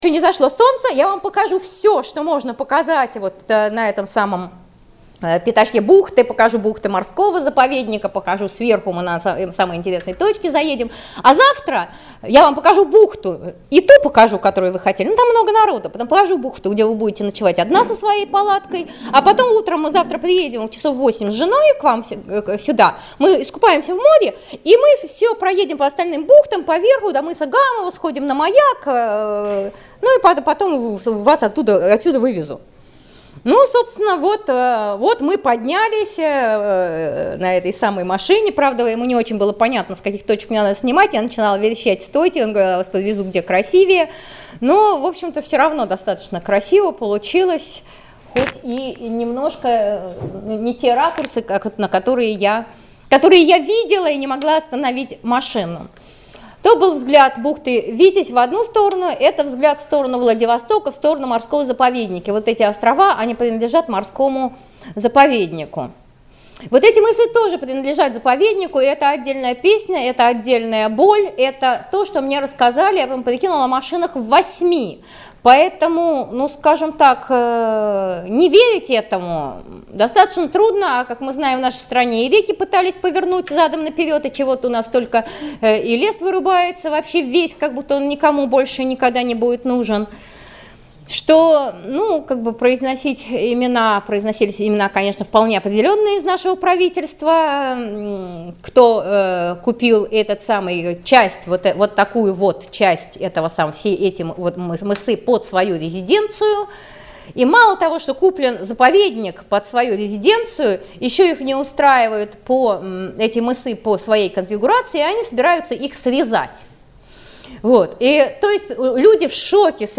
Пеший поход вдоль юго-западной оконечности Сахалина. Можно прослушать фото-аудио рассказ путешествия.